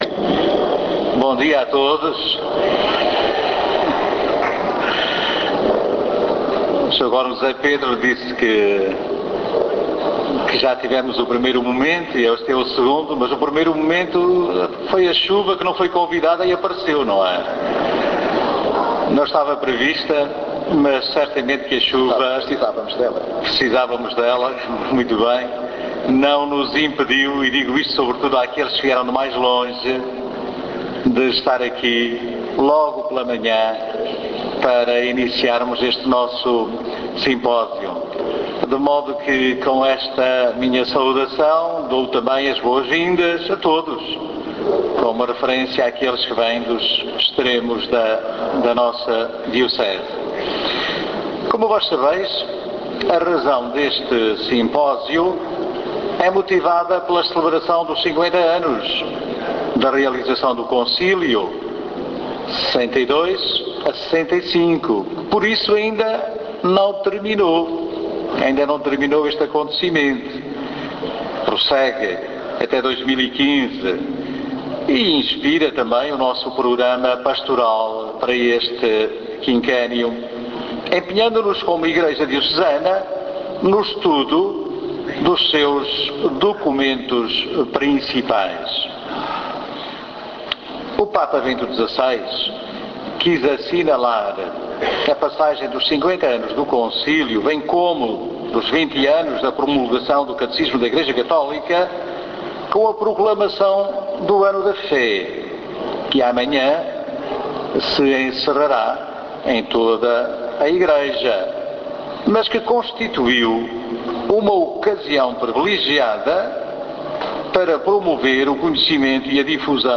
Abertura_simposio_concilio_vaticano.mp3